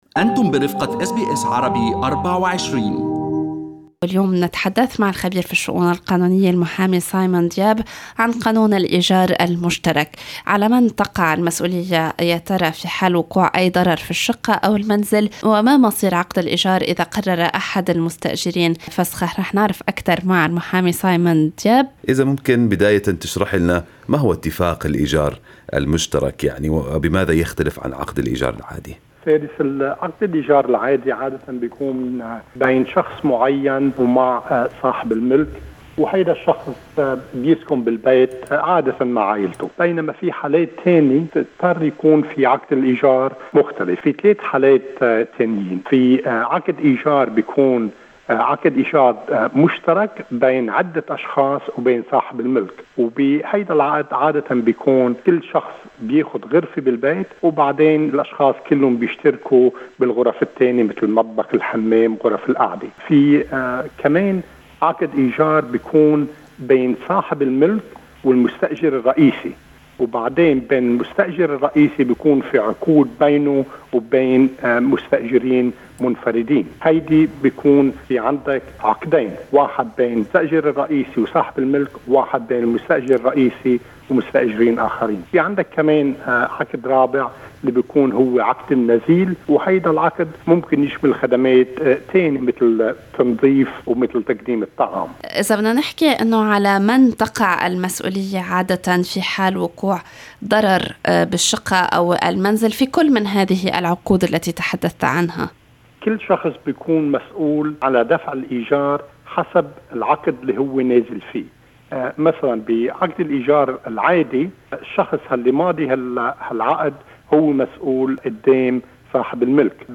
ويمكن الاستماع للقاء في الملف الصوتي المرفق.